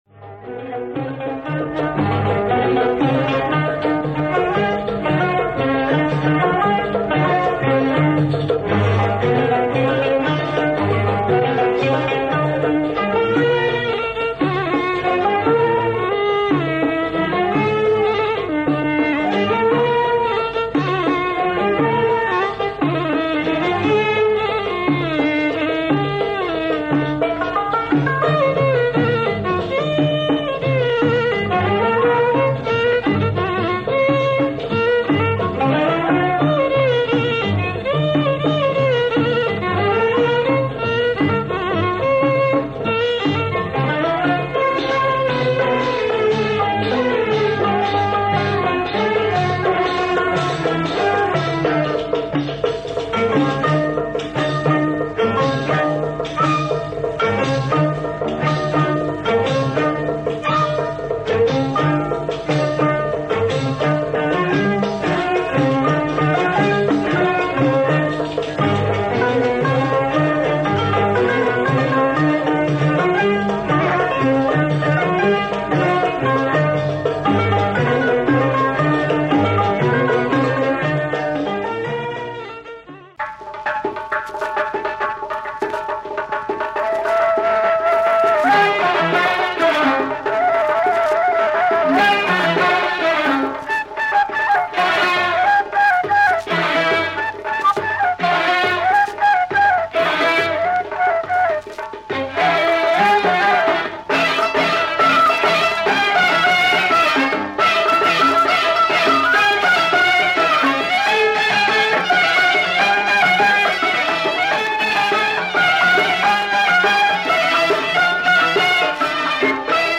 Marvellous Moroccan Mizrahi track on b-side